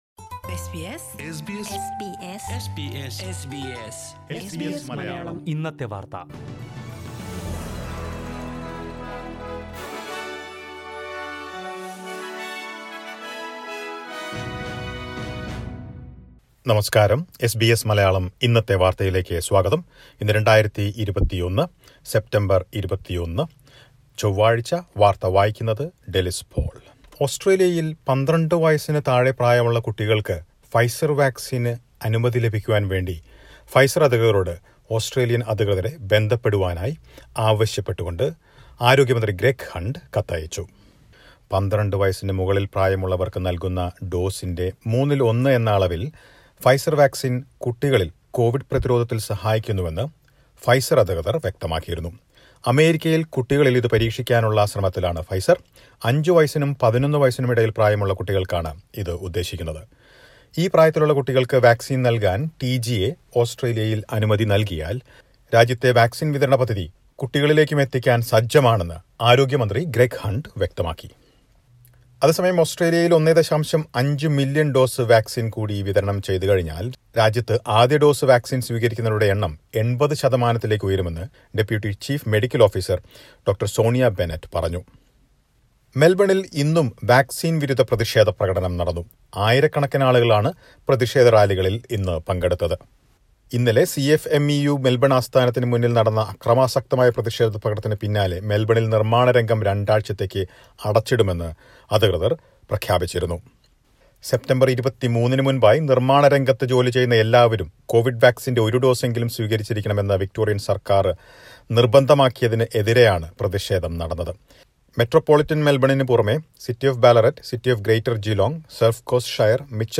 2021 സെപ്റ്റംബർ 21ലെ ഓസ്ട്രേലിയയിലെ ഏറ്റവും പ്രധാന വാർത്തകൾ കേൾക്കാം...
news_2109_0.mp3